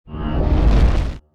engine_start_005.wav